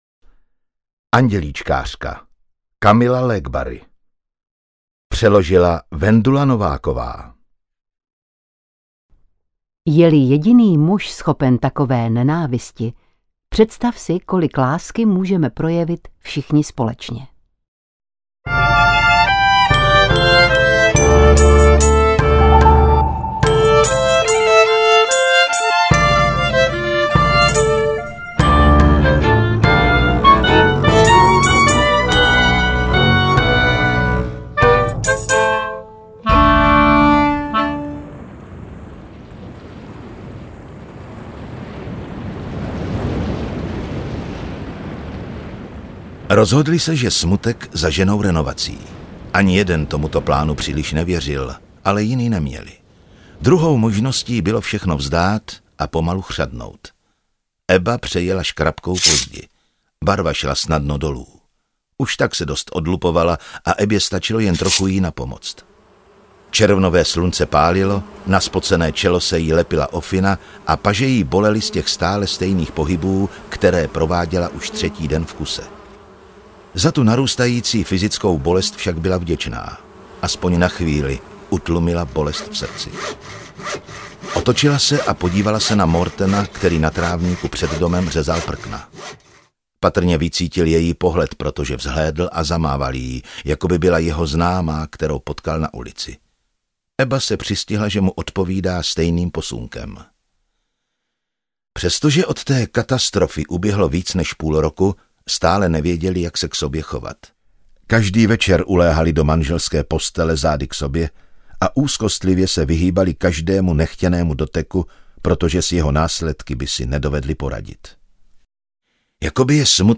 Zvuky a ruchy jsou v této audioknize opravdu strašné a rušivé, navíc je považuji za zbytečné, zvukař byl asi opravdu šílenec a hluchý k tomu!
Úplně zbytečně jsou na nahrávce zvuky zvonění telefonu, vrzání dveří, apod.
AudioKniha ke stažení, 140 x mp3, délka 14 hod. 36 min., velikost 1356,6 MB, česky